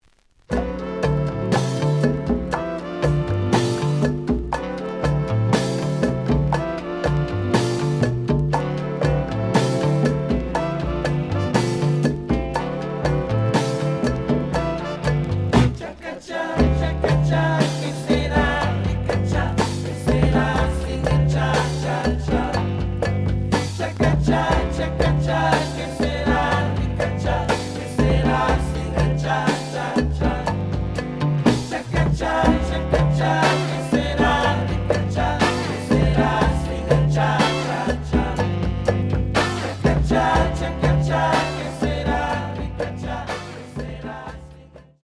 The audio sample is recorded from the actual item.
●Genre: Funk, 70's Funk
Slight click noise on middle of both sides due to a bubble.